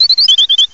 cry_not_fletchling.aif